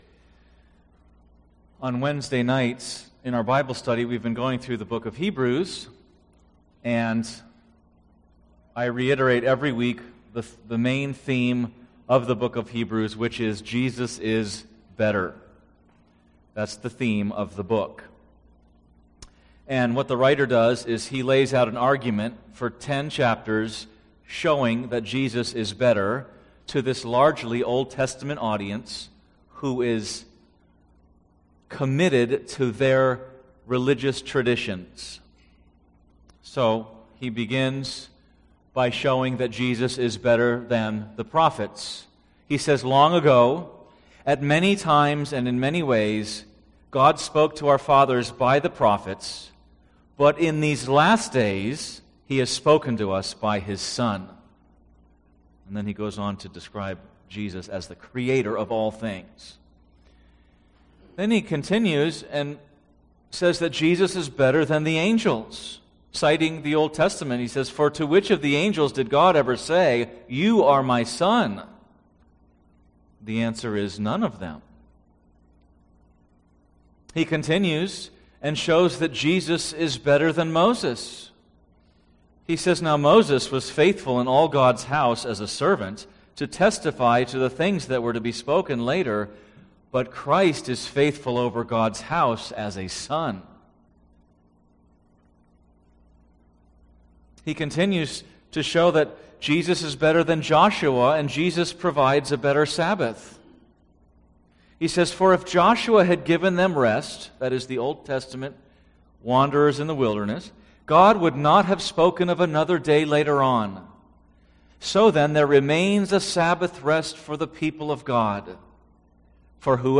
Podcast (heritage-valley-bible-church-sermons): Play in new window | Download